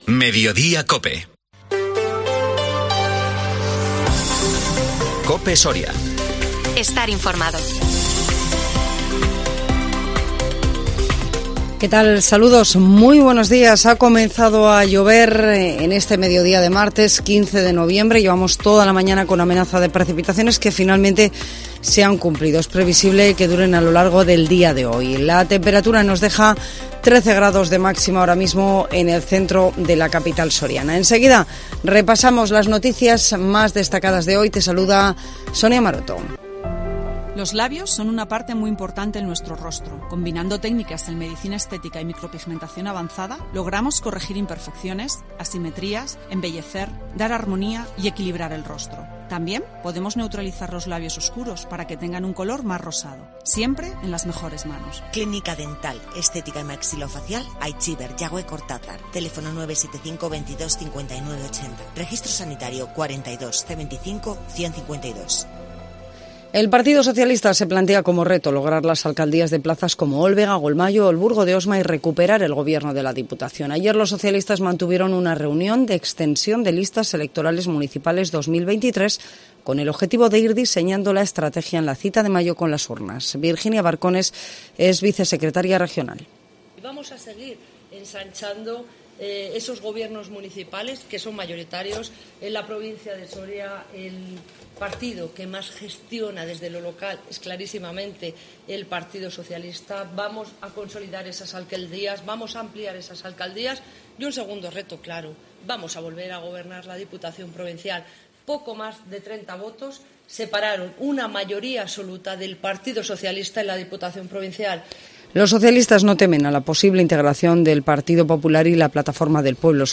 INFORMATIVO MEDIODÍA COPE SORIA 15 NOVIEMBRE 2022